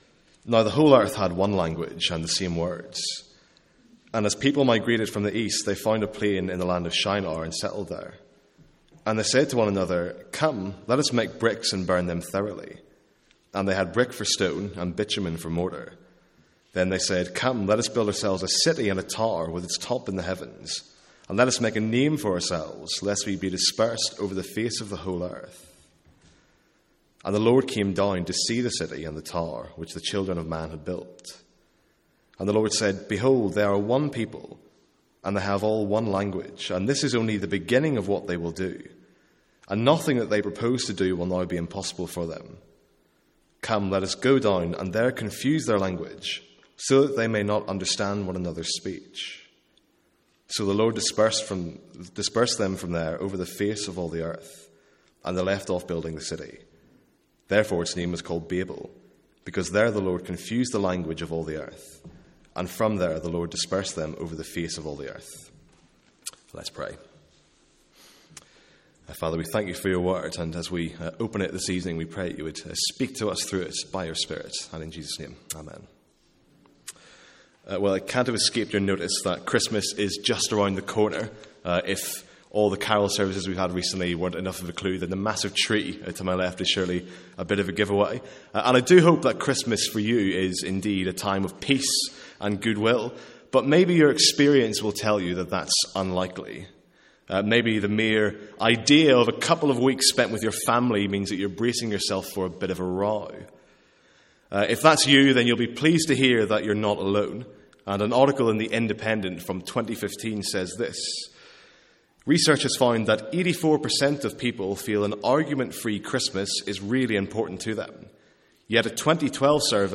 Sermons | St Andrews Free Church
From our evening series in Genesis.